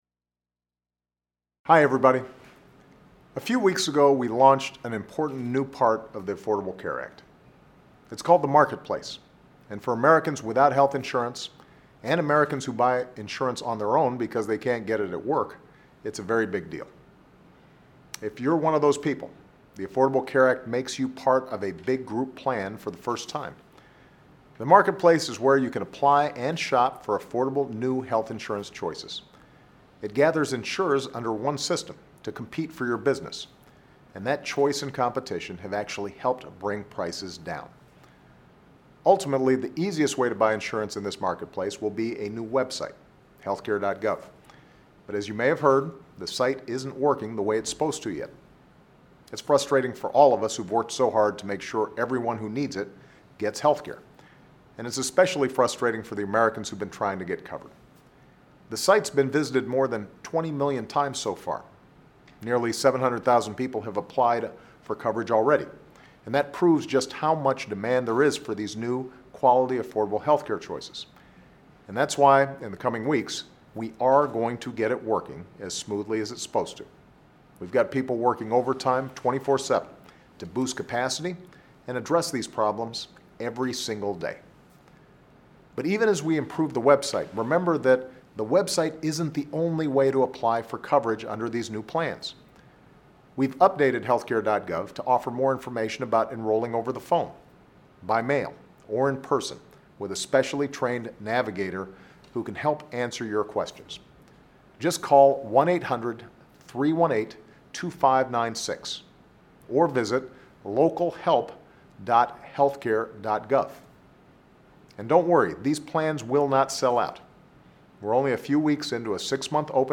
In his weekly address, President Obama discusses the launch of the Internet based Health Insurance Marketplace for the Affordable Care Act. Obama reminds listeners that the ACA allows people who have found health insurance expensive or unaffordable an opportunity to find coverage.